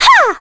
Ophelia-Vox_Attack3.wav